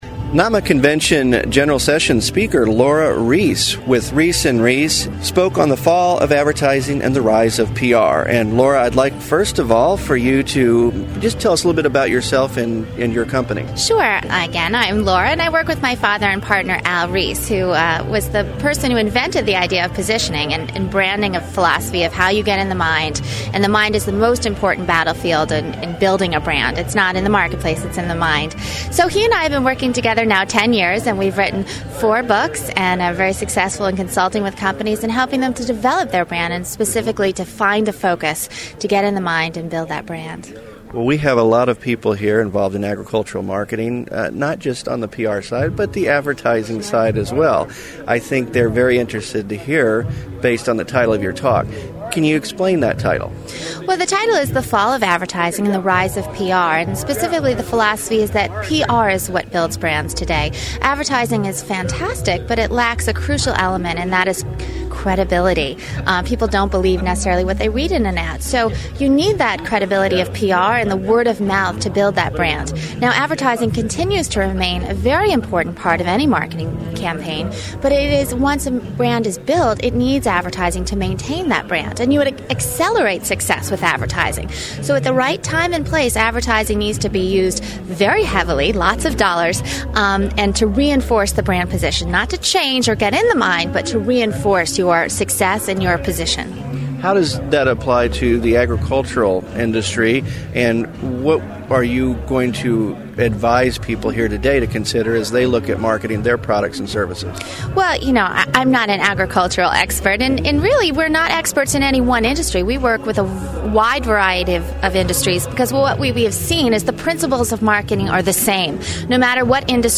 Laura Ries Interview (5 min MP3)